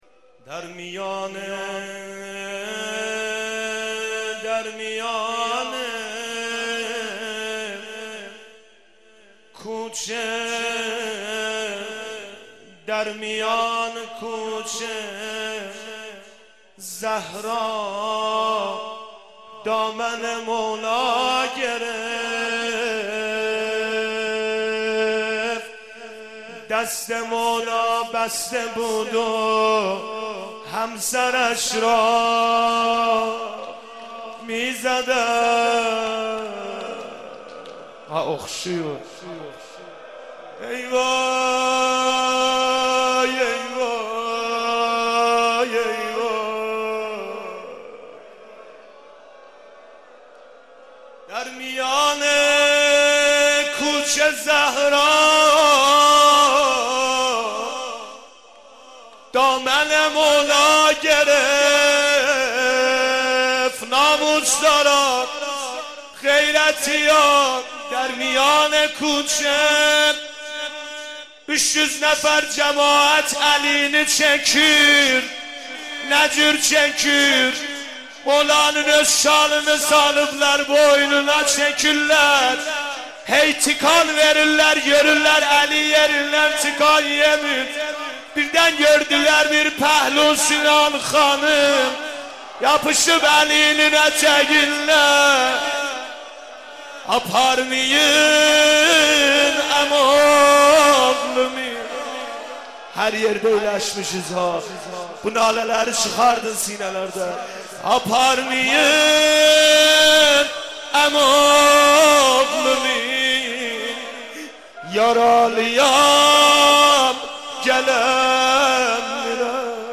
ذکر مصیبت در شهادت «حضرت زهرا(س)»